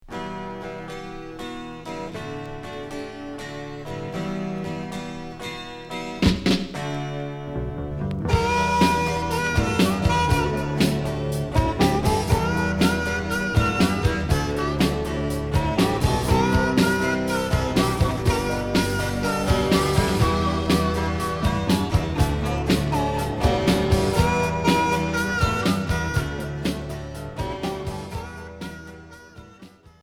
Rock bluesy Unique 45t